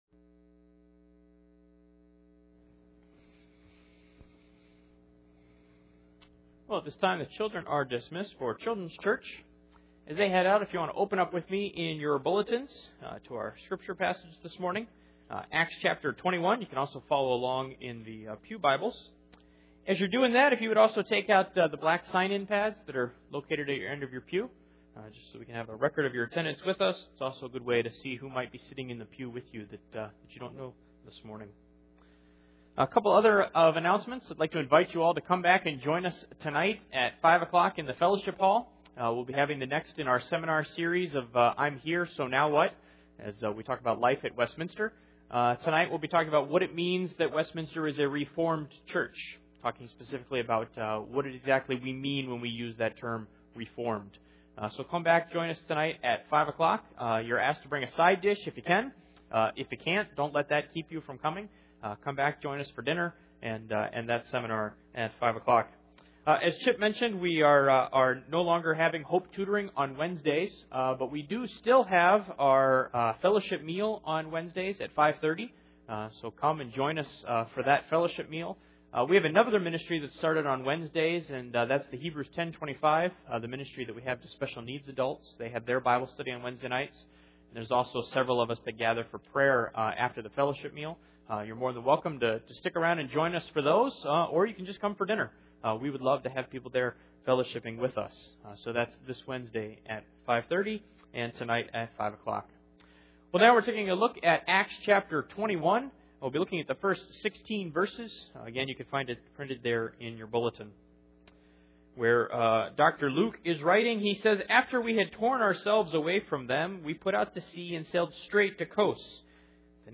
The Book of Acts Passage: Acts 21:1-16 Service Type: Sunday Morning %todo_render% « Serve God